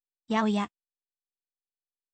yaoya